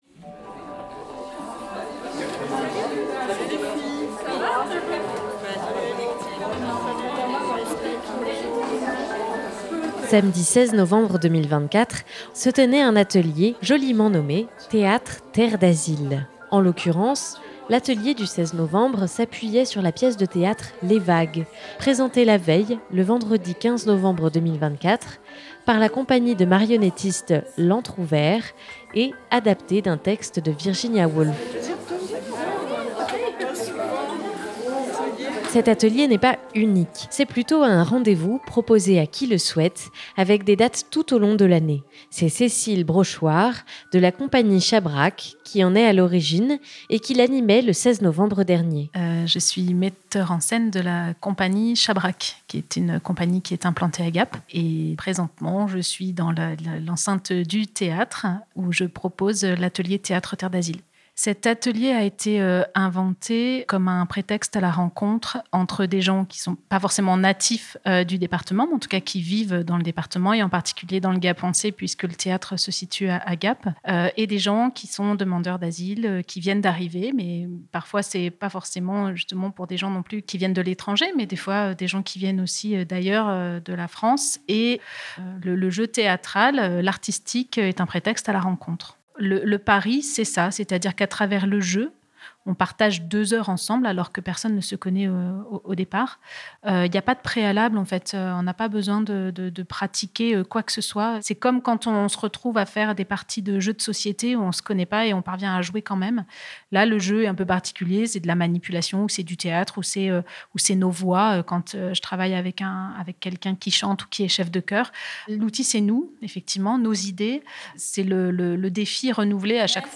Nous avons pu assister à un de ces moments de lâcher prise, de jeu et de partage, lors d'un atelier le samedi 16 novembre 2024.